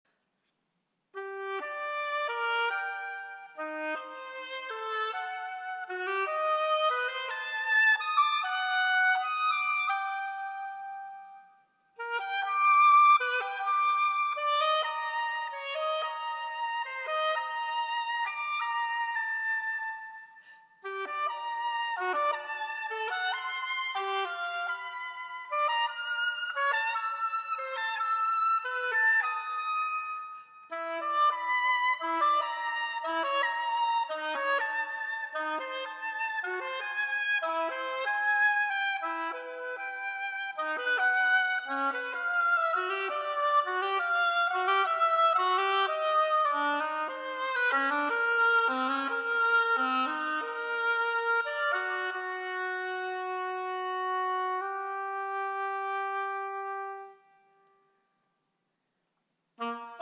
Piano
Bassoon
Piano and harpsichord